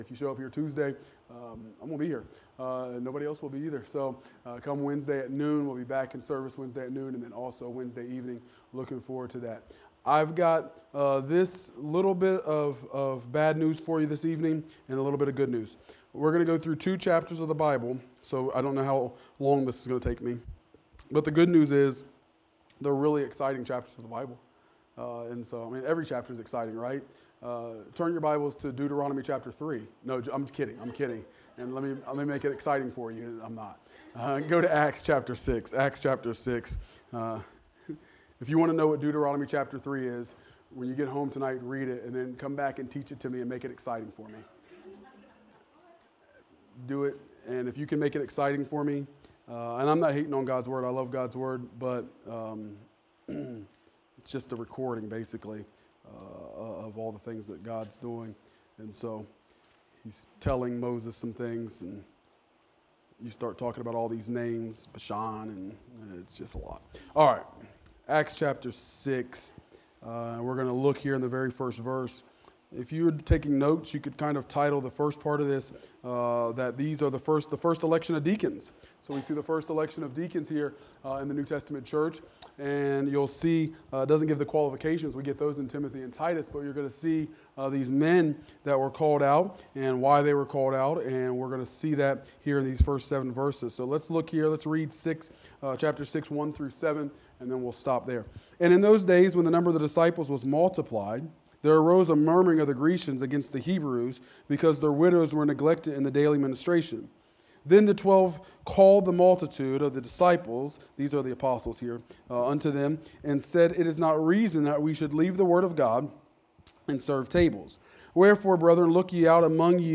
Evening Worship Service